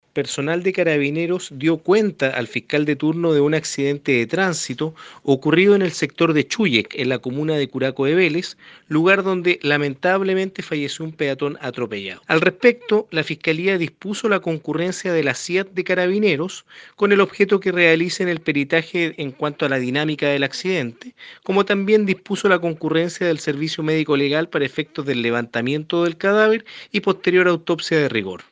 Esto fue lo señalado por el fiscal (S) Cristian Mena.